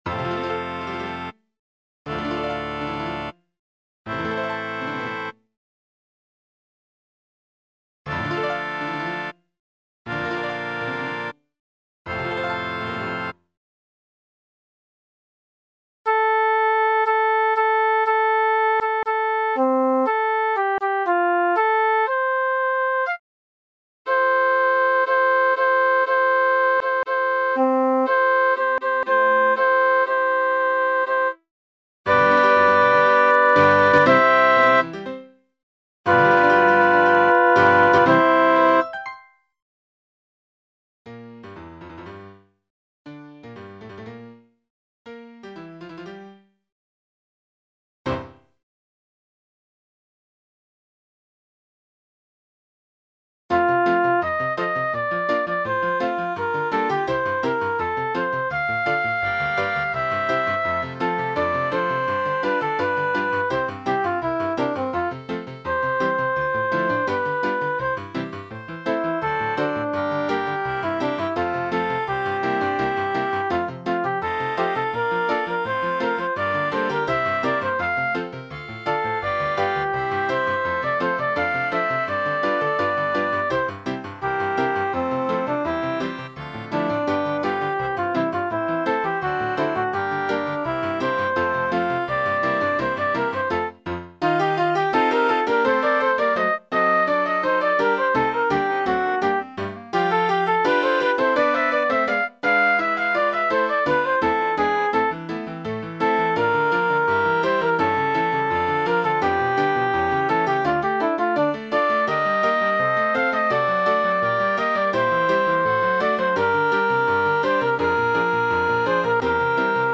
S A T B Full